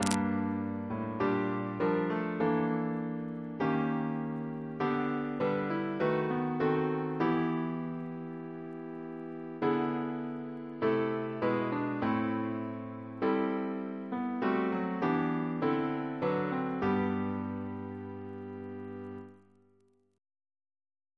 Double chant in G Composer: Richard Wayne Dirksen (1921-2003), Organist of Washington Cathedral Note: for Psalm 8